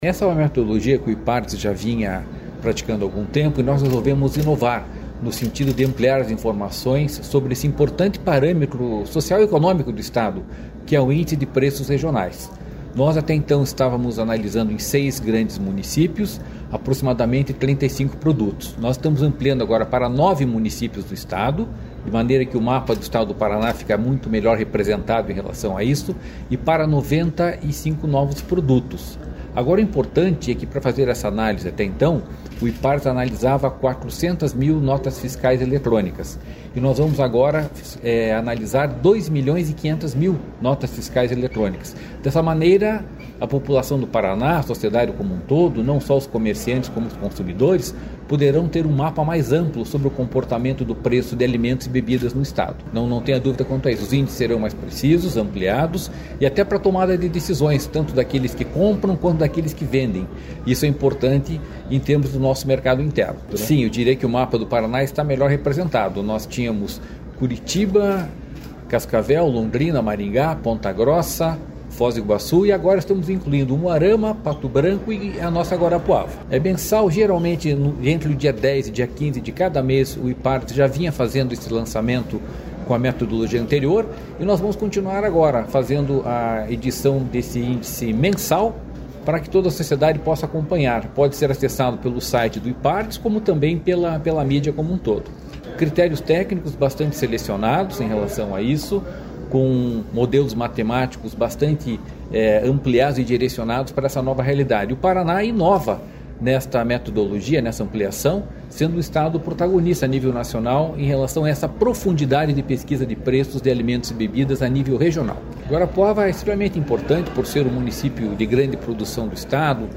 Sonora do diretor-presidente do Ipardes, Jorge Callado, sobre a ampliação de municípios e produtos pesquisados na confecção do IPR